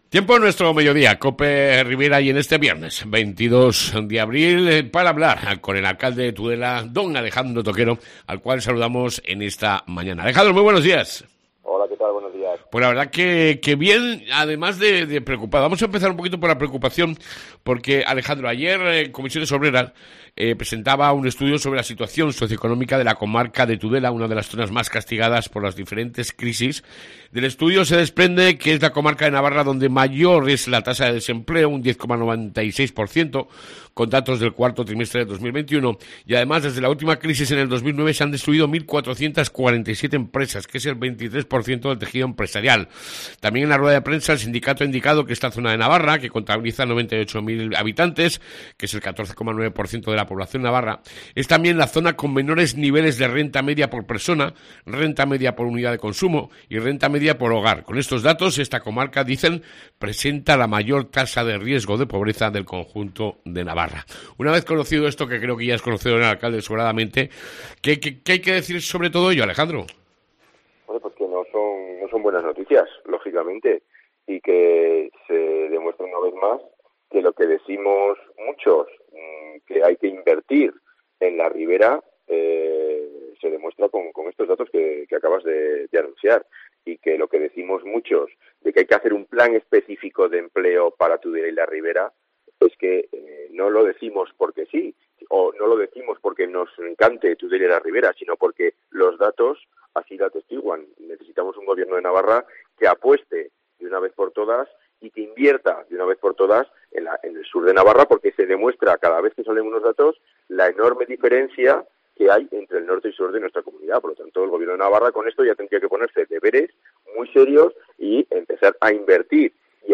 ENTREVISTA CON EL ALCALDE DE TUDELA